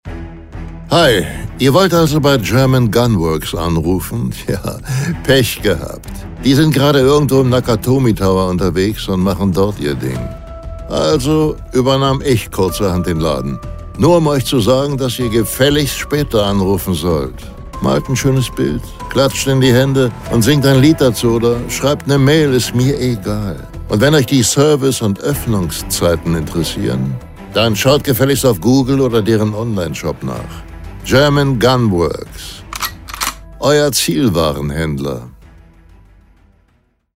Bruce Willis Telefonansage
Die Stimme der von ihm gespielten Figuren ist stets männlich und voll. Sie klingt äußerst kräftig und markant – und manchmal sogar etwas verschmitzt.